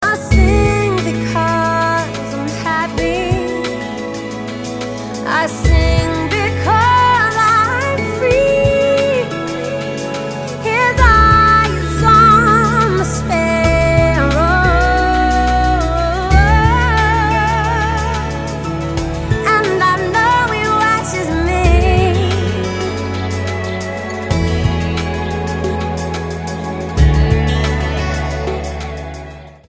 STYLE: Pop
smooth Anastasia-like vocals